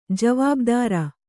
♪ javābdāra